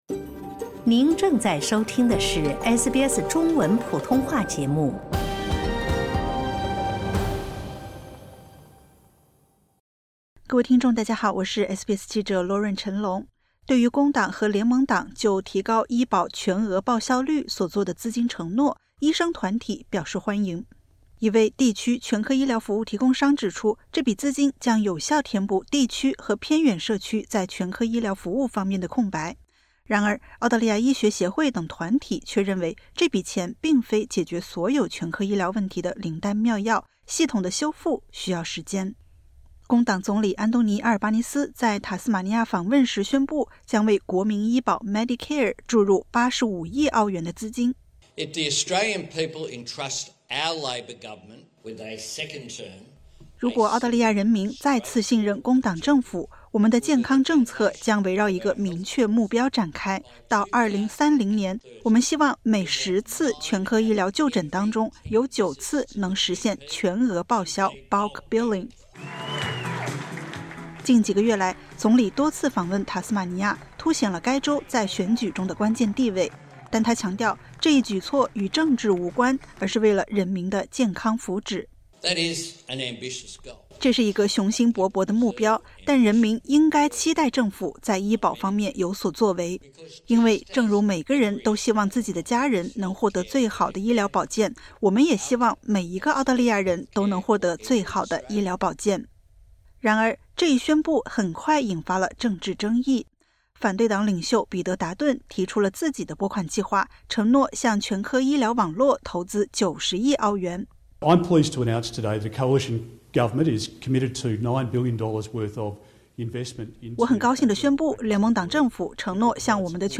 然而，澳大利亚医学协会等团体却认为，这笔钱并非解决所有全科医疗问题的灵丹妙药，系统的修复需要时间。点击 ▶ 收听完整报道。